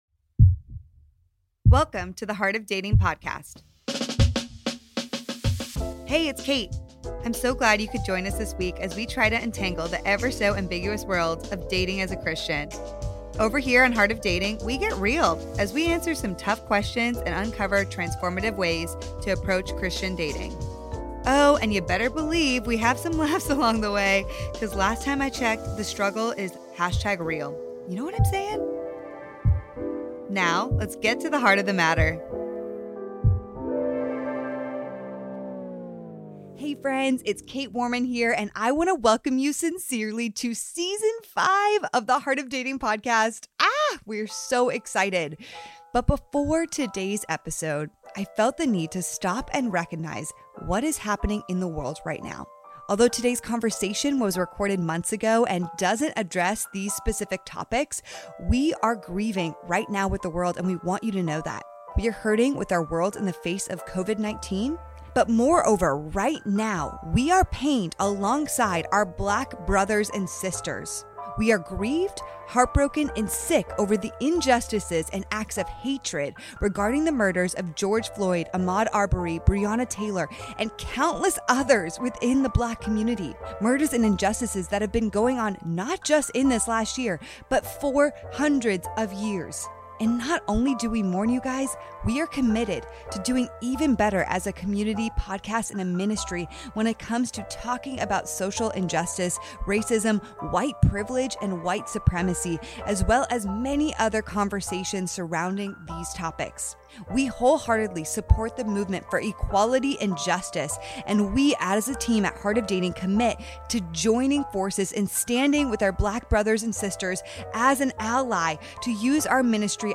an incredible conversation